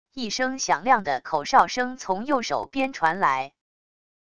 一声响亮的口哨声从右手边传来wav音频